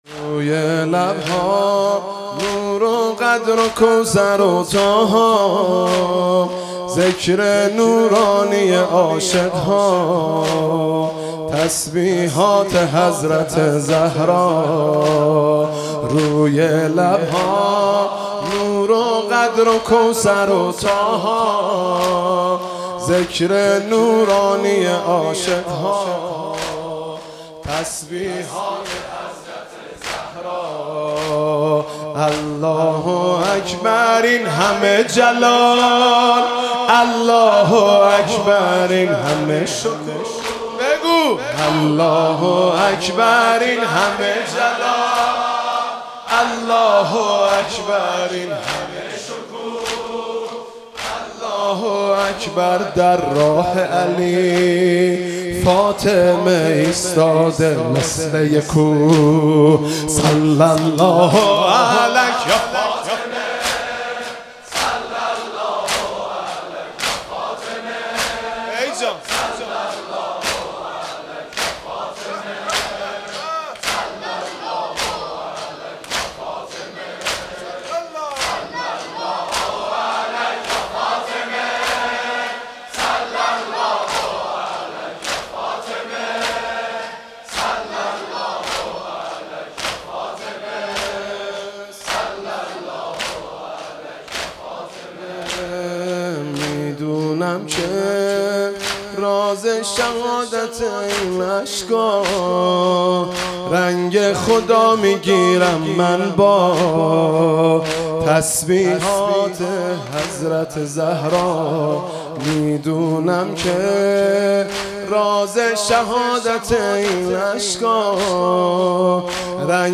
حاج مهدی رسولی
حسینیه ثارالله زنجان 28 دیماه 96 - زمینه - روی لبها نور وقدر وکوثر وطاها